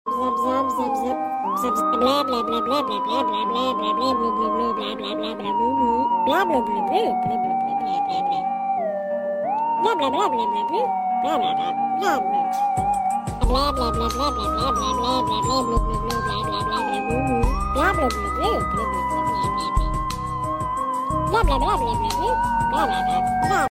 zip zip zip zip zip sound effects free download